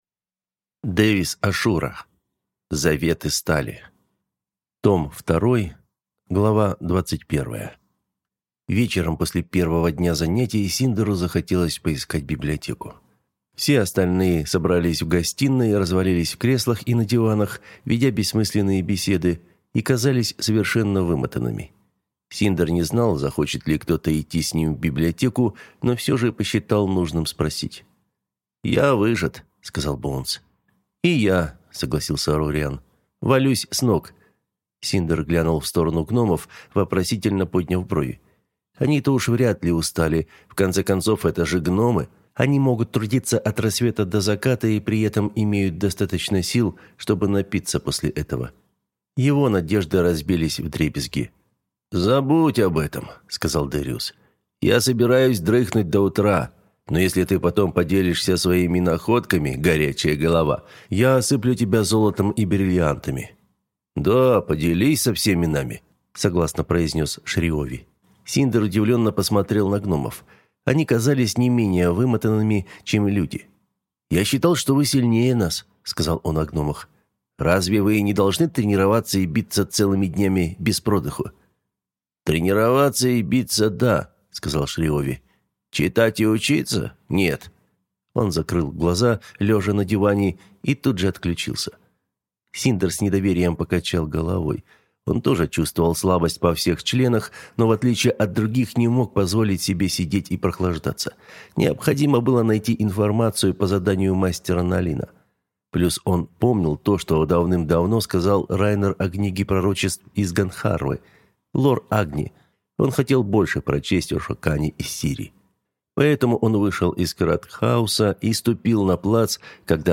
Аудиокнига Завет стали. Книга первая. Орудие предзнаменования. Часть вторая | Библиотека аудиокниг